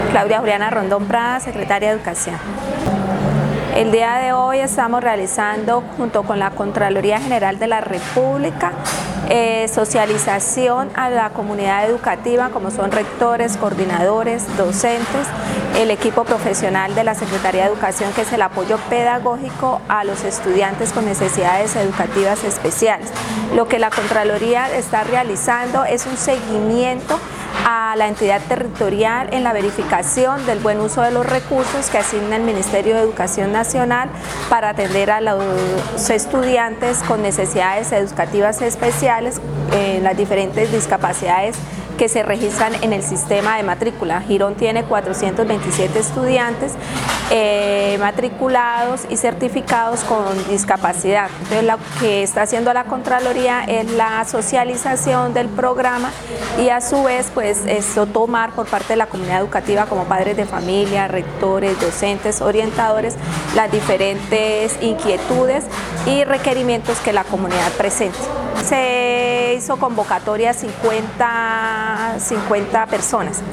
Claudia Rondón - Secretaria de Educación.mp3